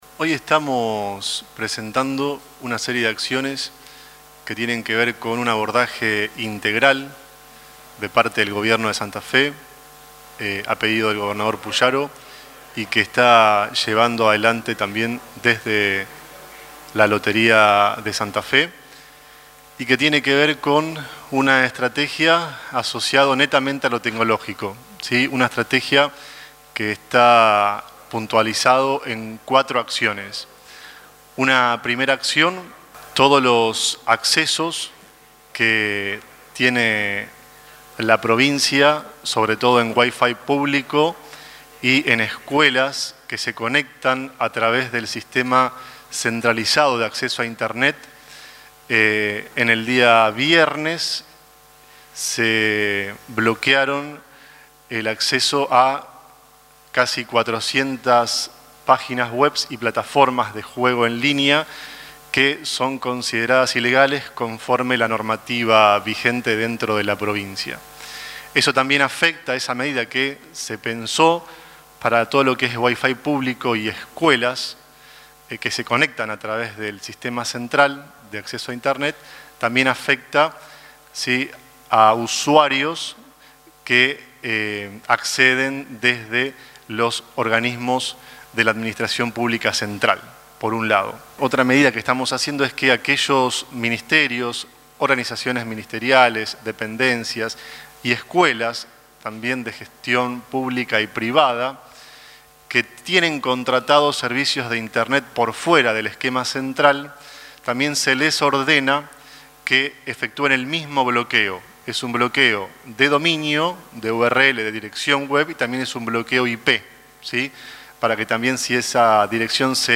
Ignacio Tabares, secretario de Tecnologías para la Gestión